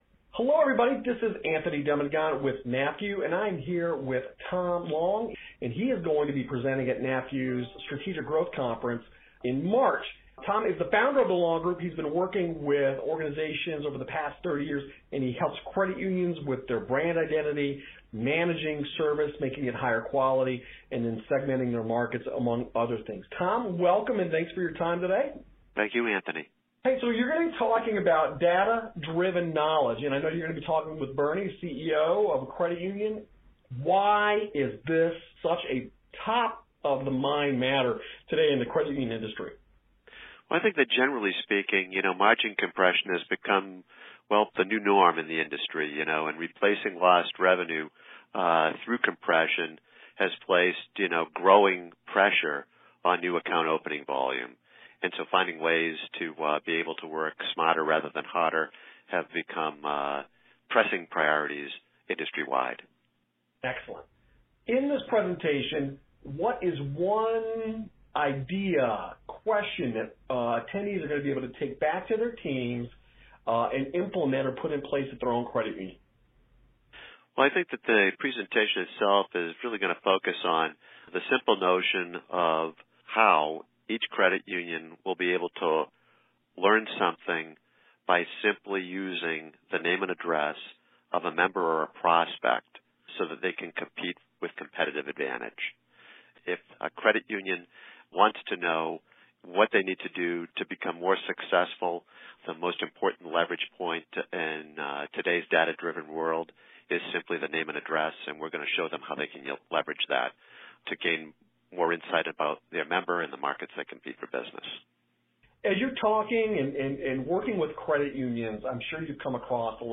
Interview transcript available below.